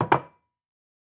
Coffee Cup Set Down Sound
household